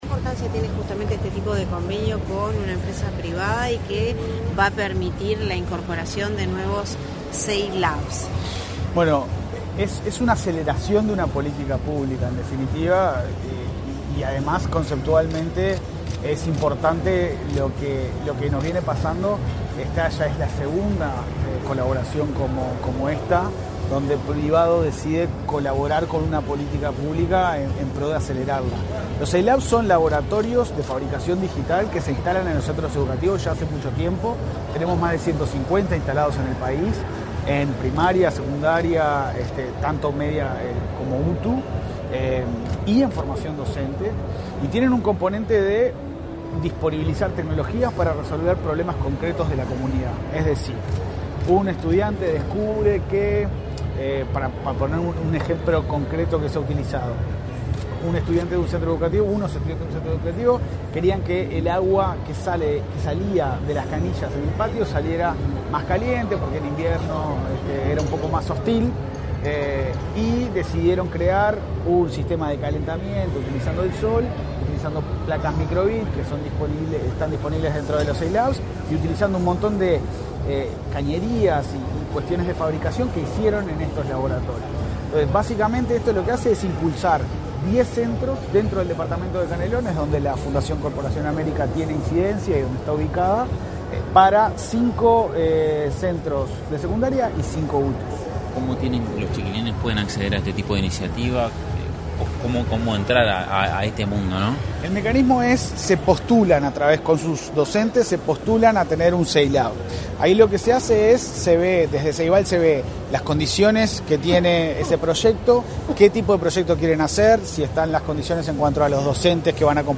Declaraciones a la prensa del presidente de Ceibal, Alejandro Folgar
Declaraciones a la prensa del presidente de Ceibal, Alejandro Folgar 28/05/2024 Compartir Facebook X Copiar enlace WhatsApp LinkedIn Ceibal y la Corporación América Airports, empresa que gestiona Aeropuertos Uruguay, acordaron, este 28 de mayo, una alianza para el desarrollo de una nueva etapa del programa Ceilab en Canelones. Tras el evento, el presidente de Ceibal, Alejandro Folgar, realizó declaraciones a la prensa.